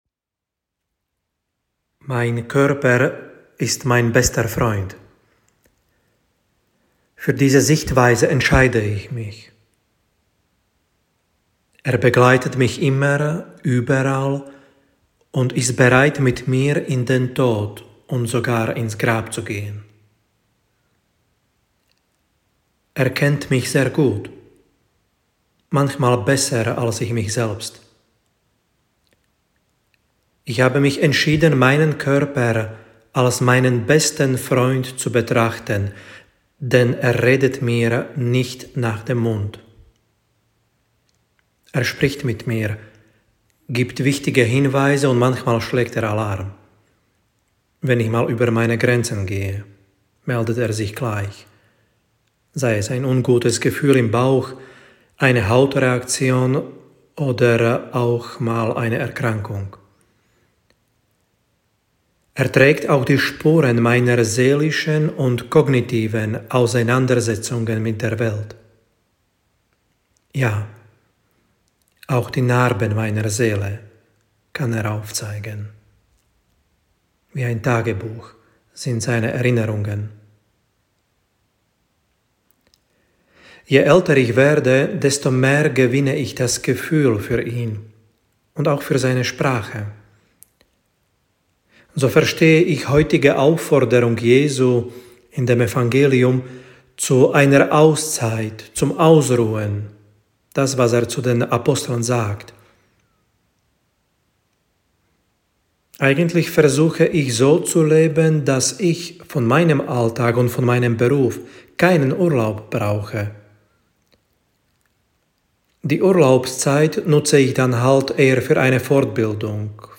Predigttext Eph 2,13-17